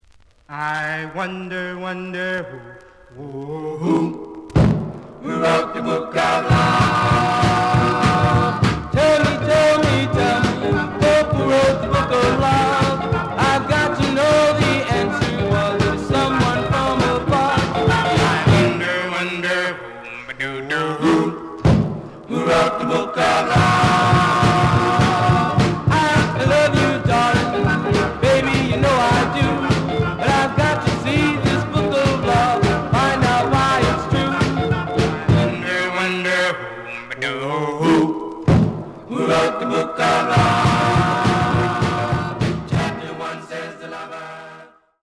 The audio sample is recorded from the actual item.
●Genre: Rhythm And Blues / Rock 'n' Roll
A side is slight cracking sound.)